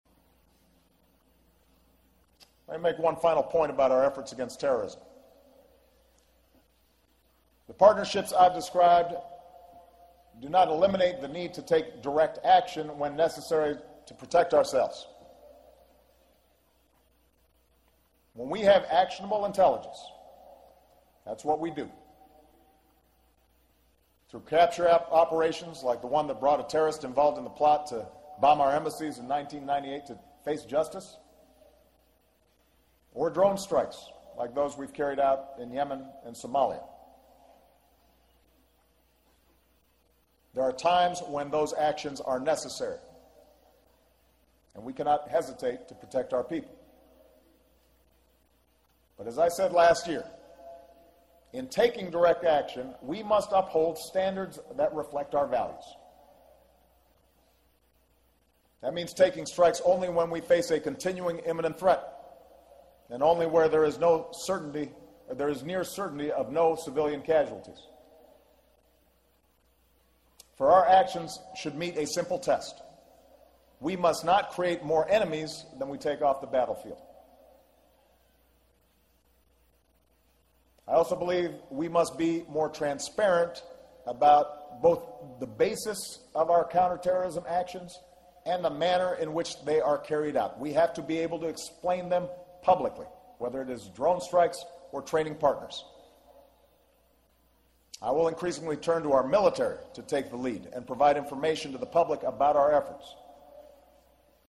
公众人物毕业演讲 第109期:奥巴马美国军事学院(12) 听力文件下载—在线英语听力室